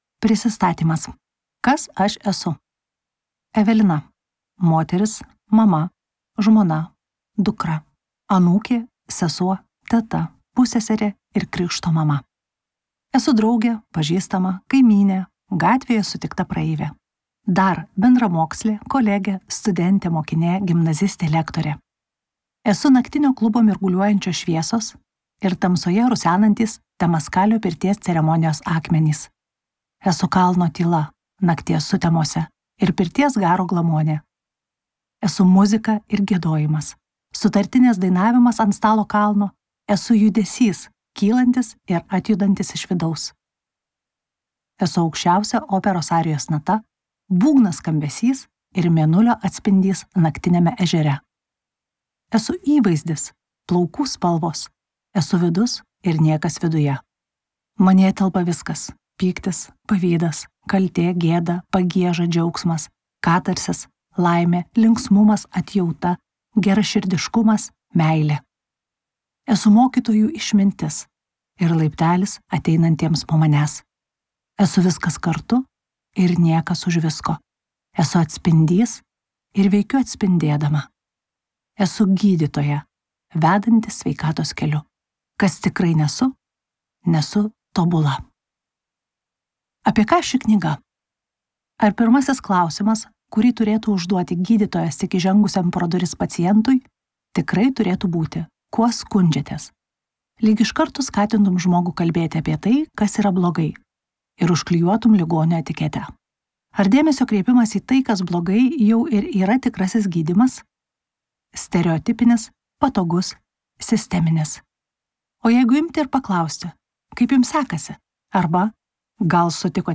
Audioknygos leidybą iš dalies finansavo Lietuvos kultūros taryba.